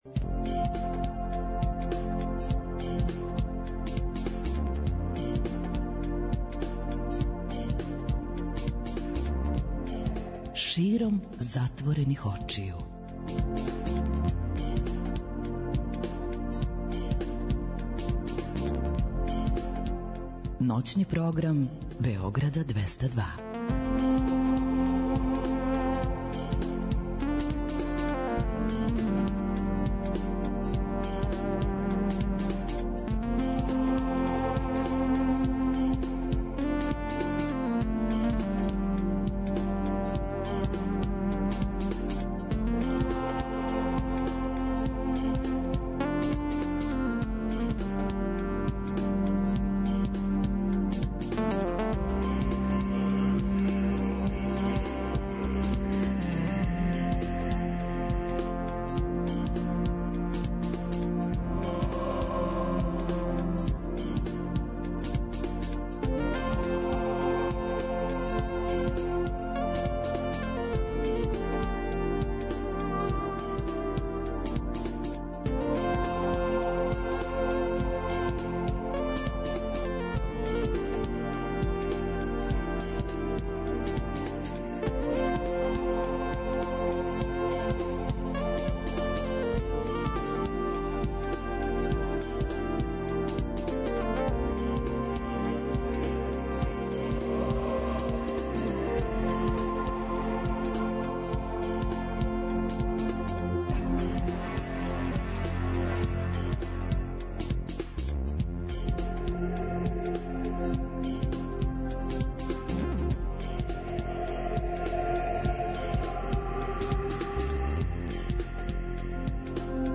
Тако некако изгледа и колажни контакт програм 'САМО СРЦЕМ СЕ ДОБРО ВИДИ'.